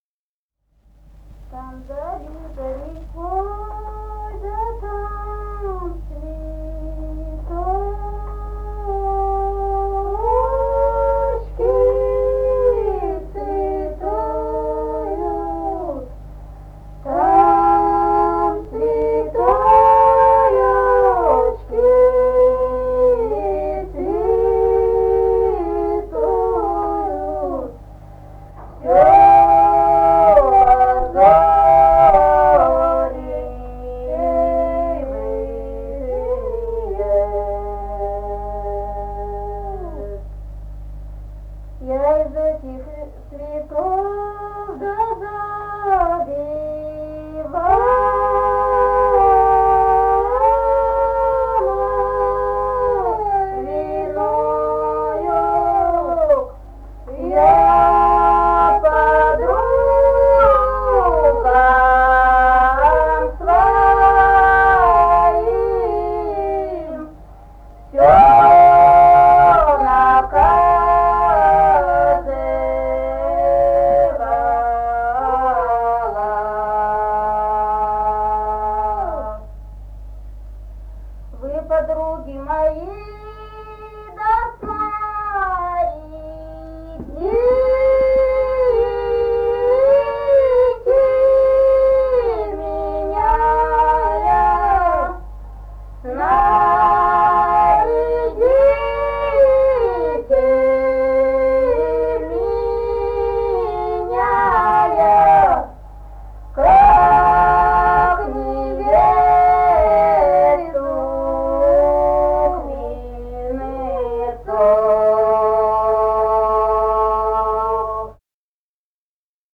Русские народные песни Красноярского края.
в с. Бражное Канского района, 1956.